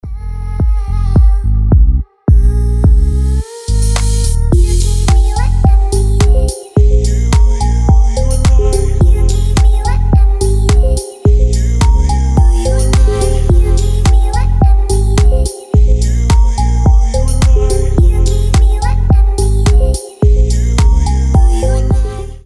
• Качество: 320, Stereo
deep house
атмосферные
спокойные
расслабляющие
космические
Chill